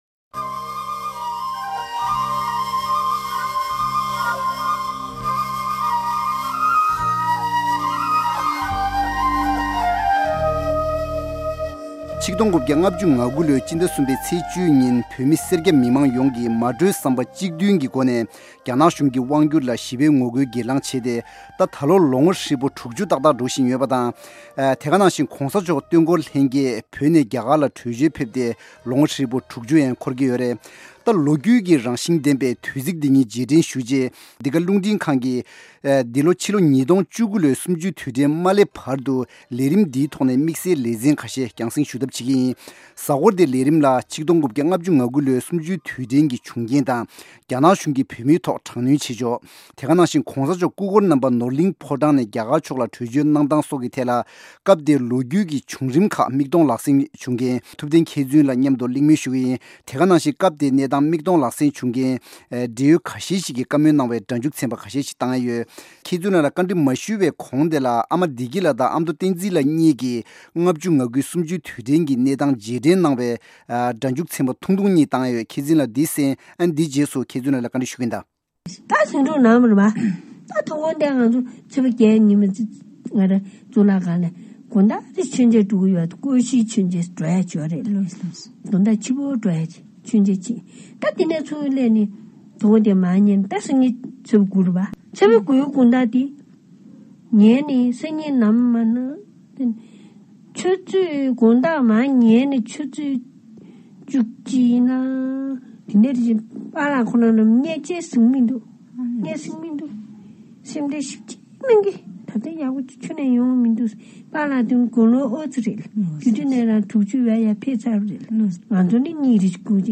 དེ་བཞིན་སྐབས་དེའི་གནས་སྟངས་མིག་མཐོང་བྱུང་མཁན་འབྲེལ་ཡོད་ཁ་ཤས་ཤིག་གིས་བཀའ་མོལ་གནང་བའི་སྒྲ་འཇུག་འགའ་ཞིག་གཏོང་རྒྱུ་ཡོད།།